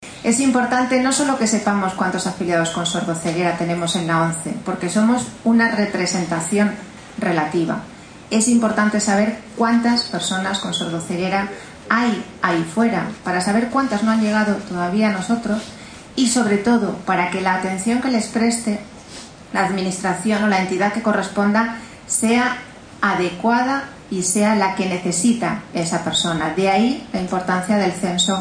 El acto central se celebró en el Complejo Deportivo y Cultural de la ONCE en Madrid, con el lema “Caminando sin barreras”.